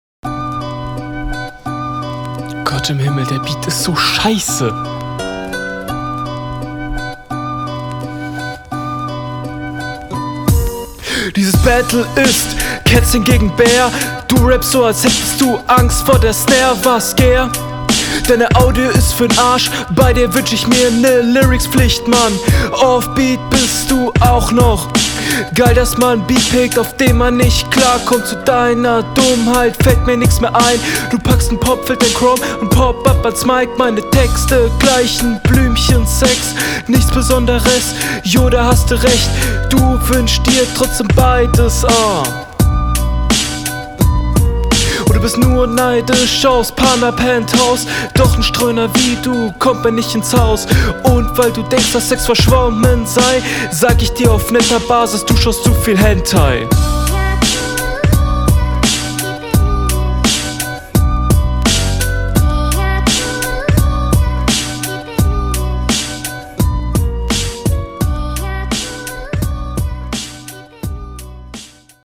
Sound wieder gut, Flowlich auch ok, eine Zeile einfach ausgelasssen und das atmen stört.
Du bist besser auf dem beat!
Onbeat, Flow ist solide.